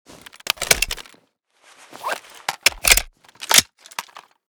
AR_empty_reload.ogg.bak